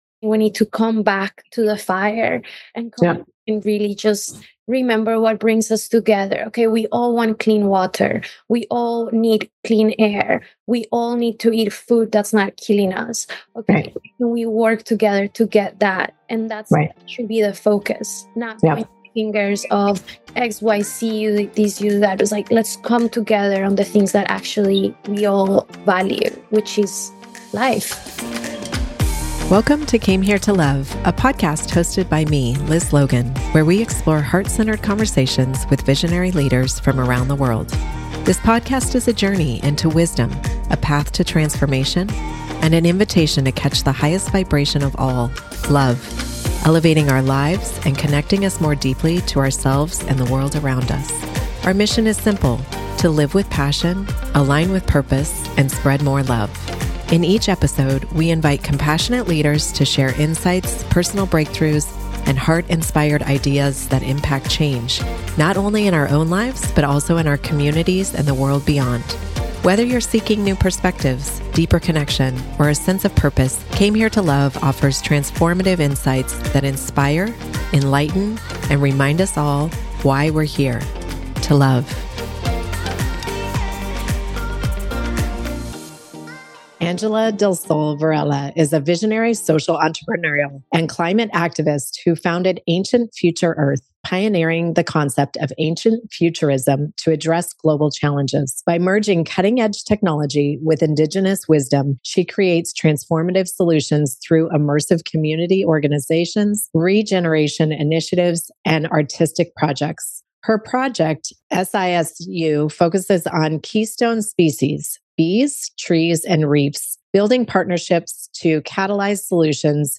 The conversation delves into the importance of honoring indigenous artisans, the power of personal growth through love and alignment, and how creativity and compassion are driving positive environmental change.